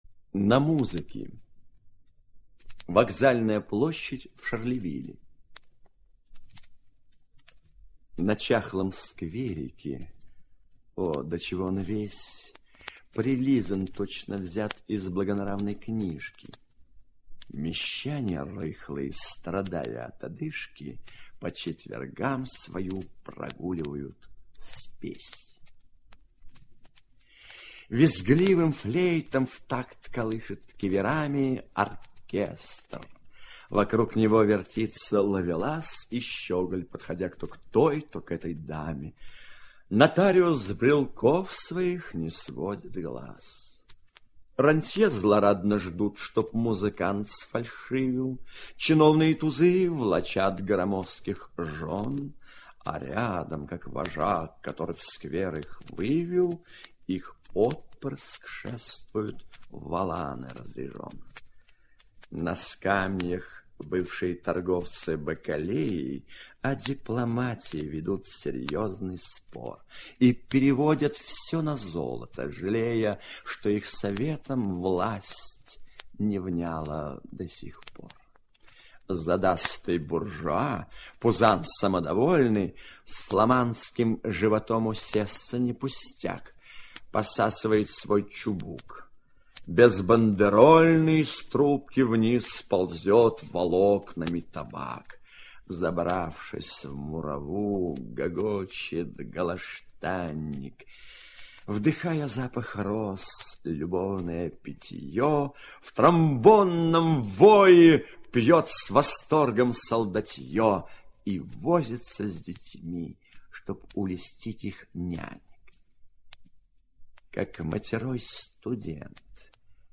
Рембо Артюр – На музыке (читает Эдуард Марцевич) – 003